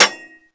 metalsolid7.wav